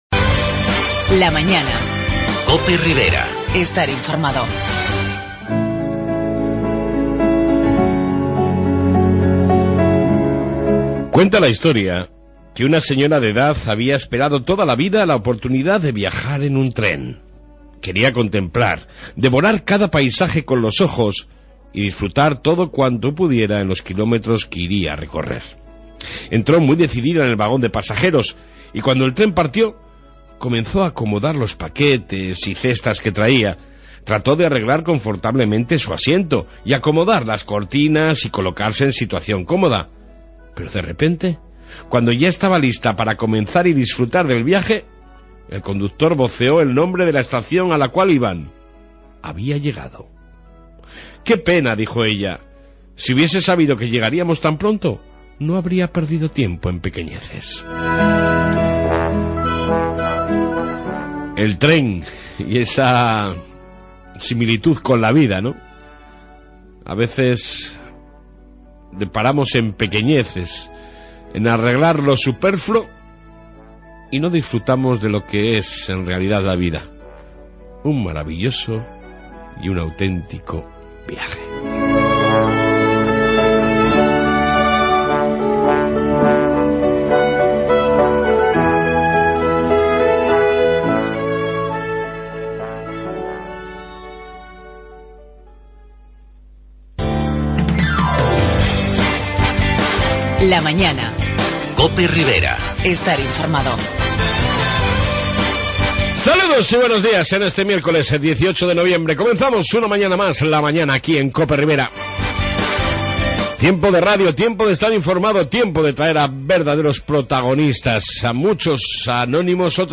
Reflexión diaria, informe Policia municipal, Noticias riberas y entrevista